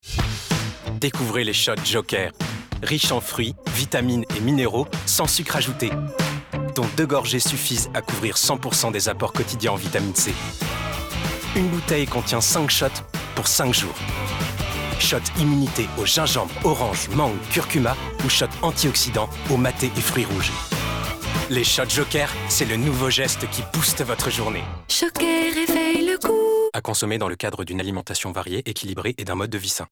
Démo voix 1